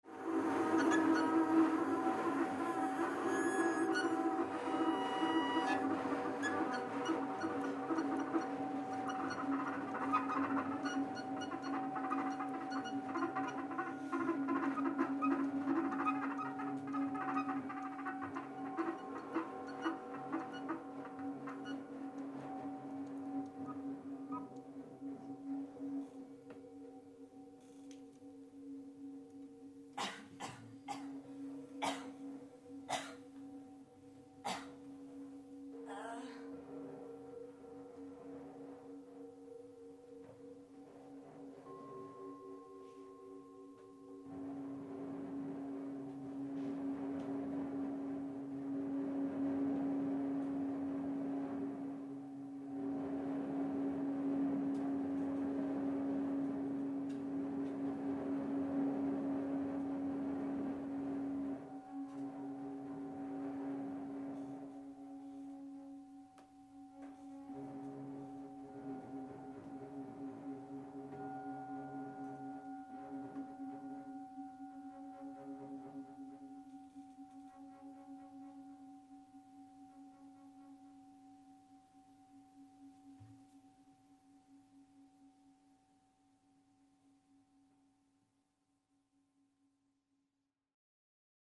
un dernier petit extrait de ce concert